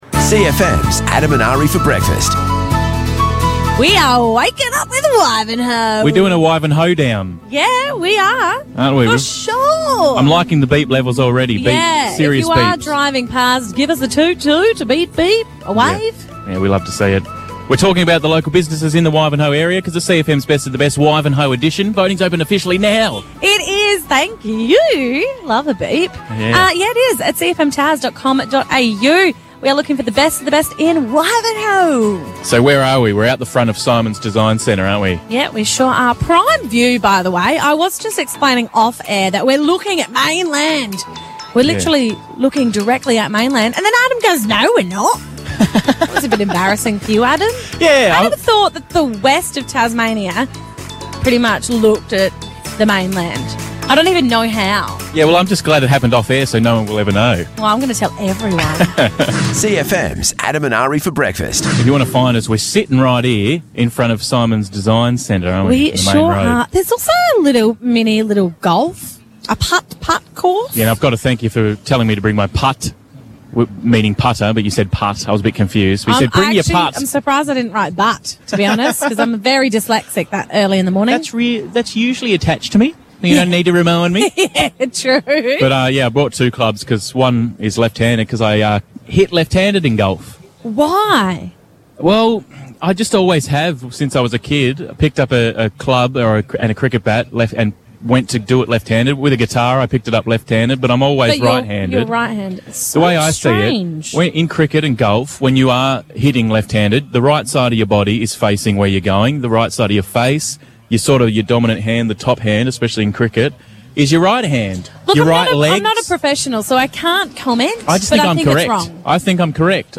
FULL SHOW: Live from Wivenhoe